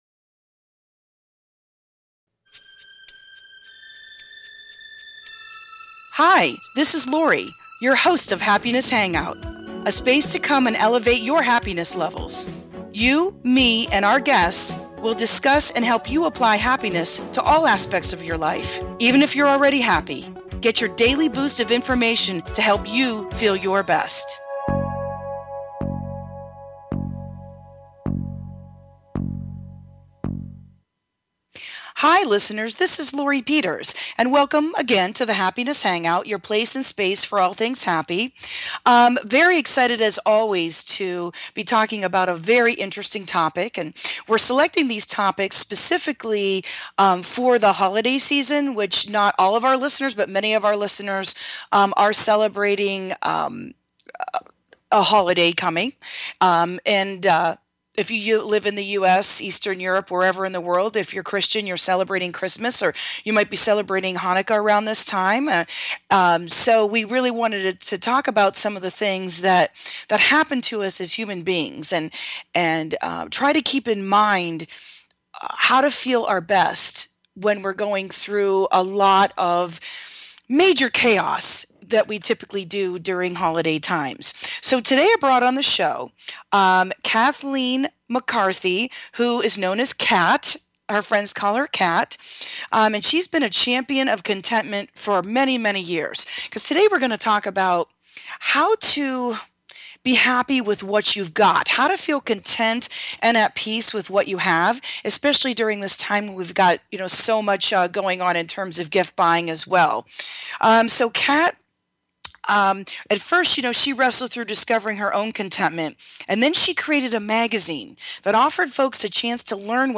Talk Show Episode
Come hang out with us as we discuss thought provoking questions with audience participation.